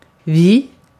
Ääntäminen
IPA: /viː/